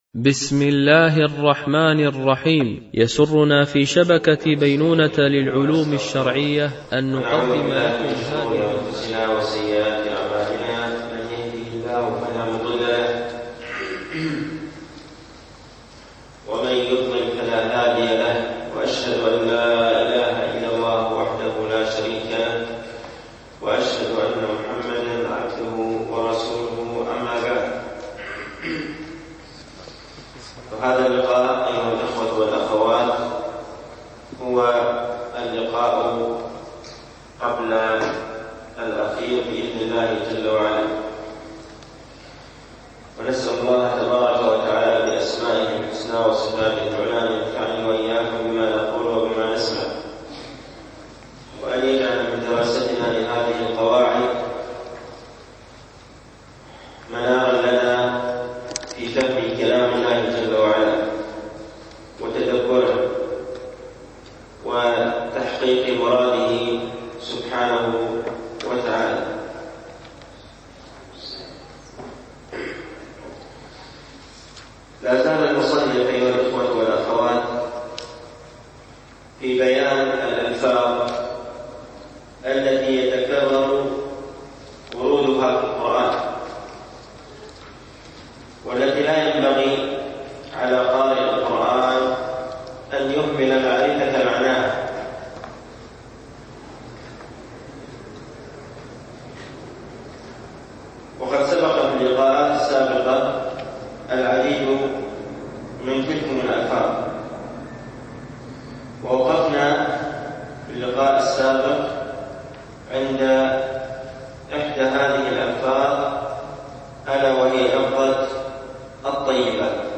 شرح أصول وكليات من أصول التفسير وكلياته للسعدي ـ الدرس التاسع